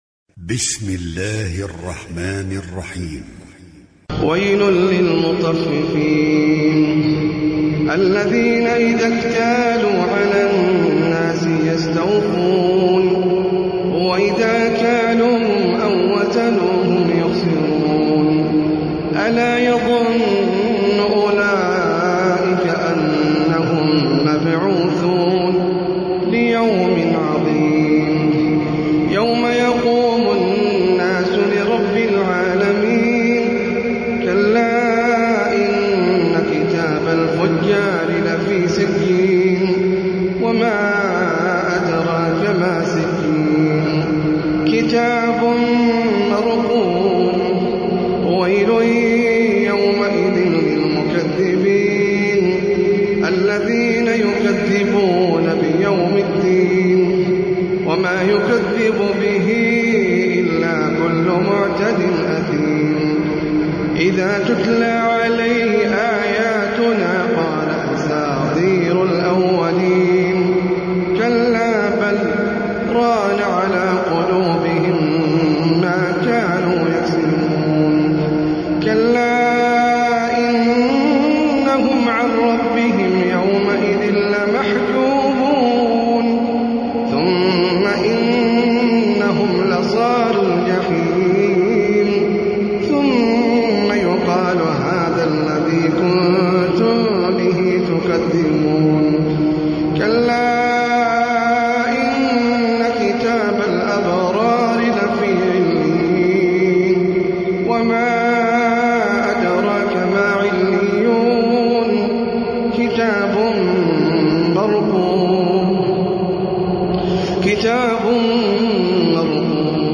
سورة المطففين - المصحف المرتل (برواية حفص عن عاصم)
جودة عالية